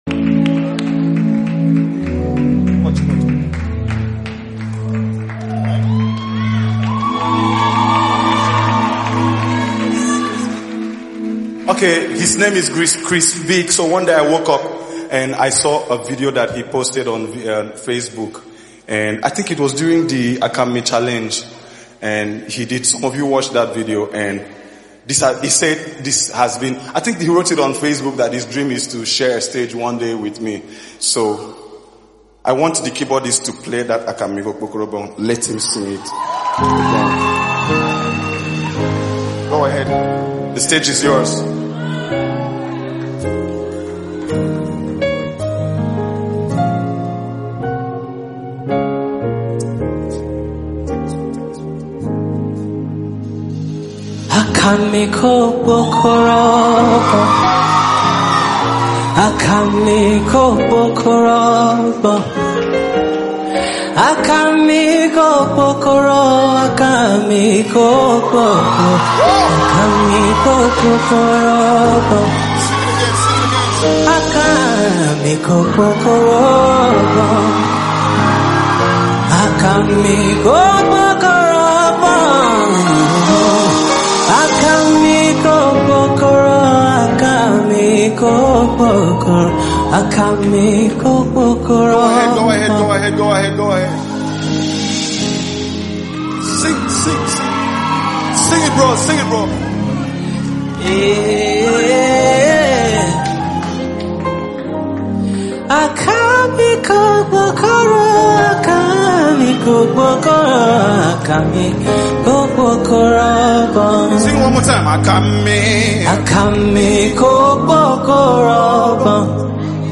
What an incredible voice and anointing!!!